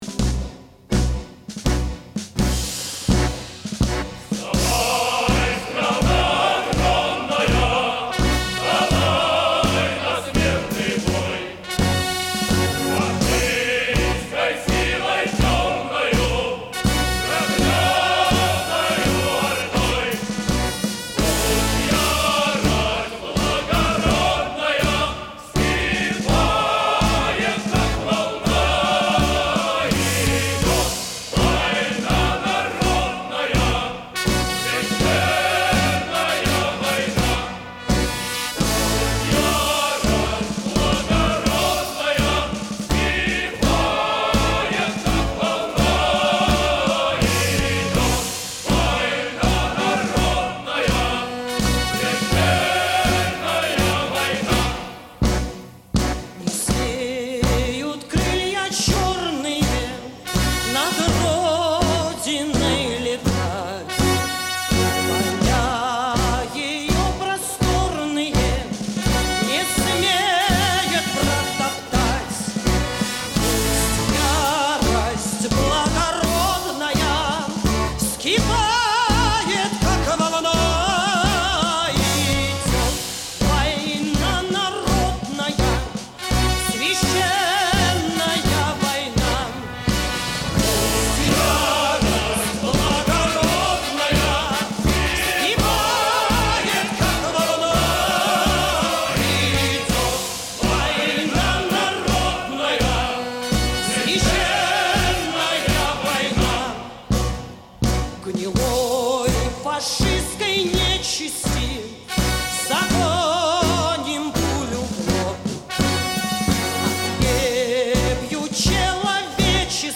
Песня отличается ярким и эмоциональным исполнением